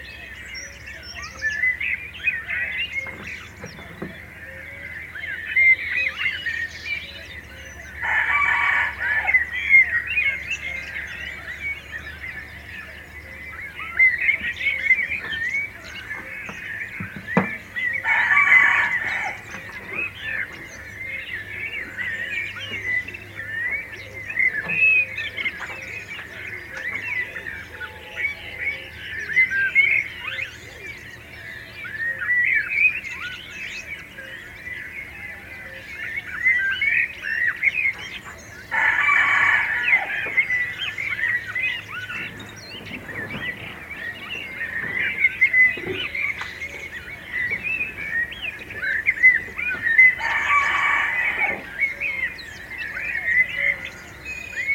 KFjoLRFqz0z_morning-rooster-suite-des-diapo.mp3